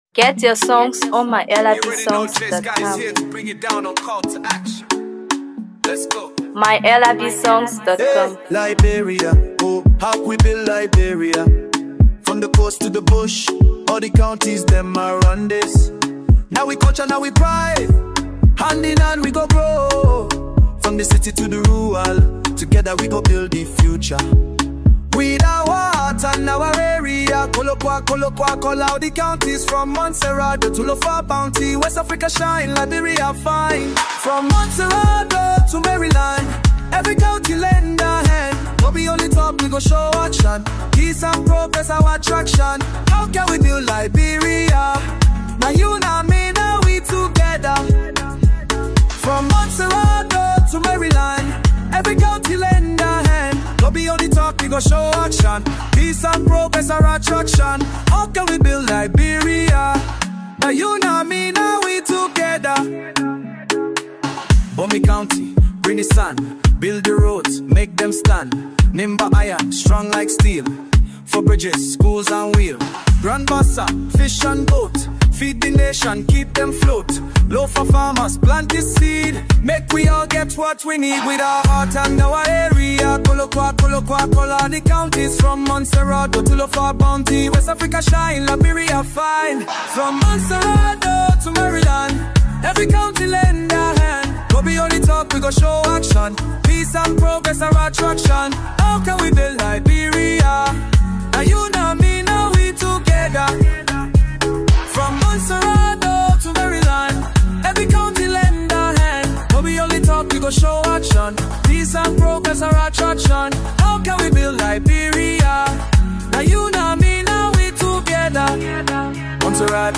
Afro Pop
vibrant rhythms and sharp lyricism
With its forward‑looking tone and empowering chorus